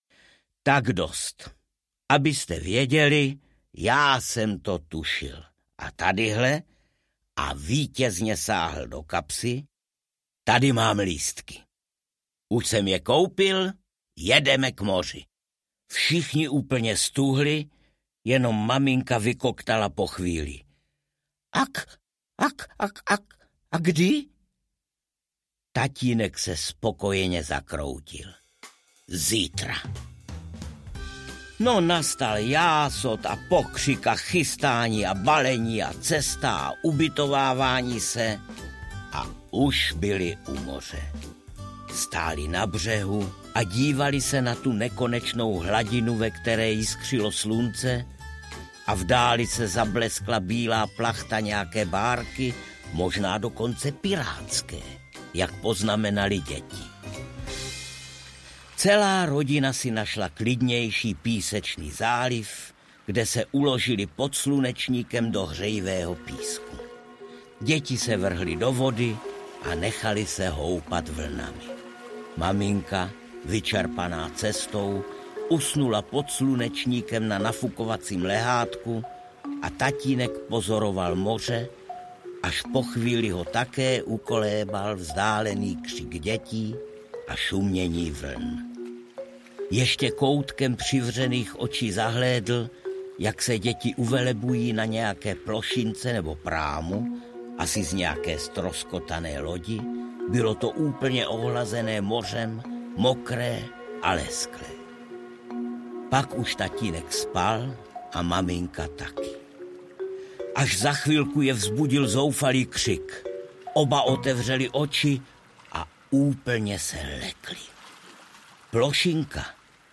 Tatínek 002 audiokniha
Ukázka z knihy
• InterpretArnošt Goldflam